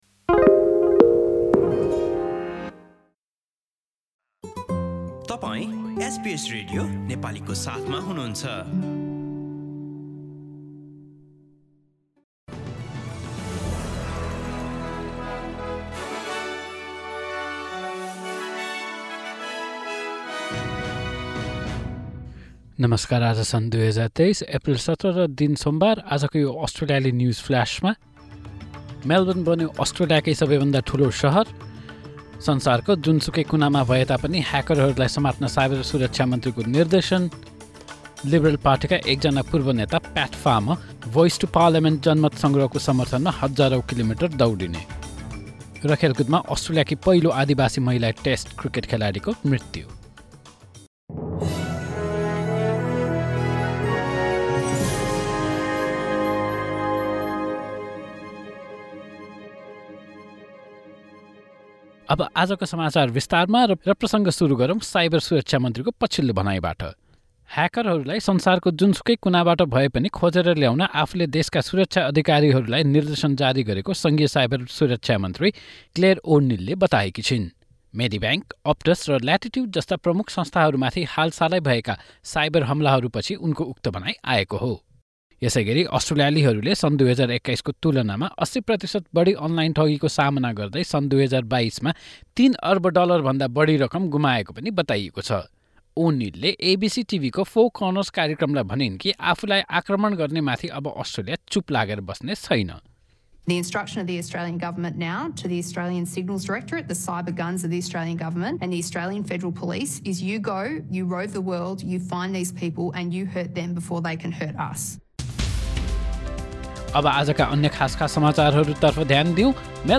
एसबीएस नेपाली अस्ट्रेलिया न्युजफ्लास: सोमवार, १७ एप्रिल २०२३